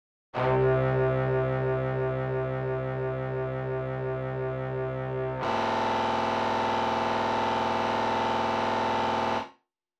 Synplant2 producing weird sound during sustained playing.
And if I hold any notes too long you get that wonky signal instead of brass.
But seriously it sounds like something in your patch is getting modulated, no idea what.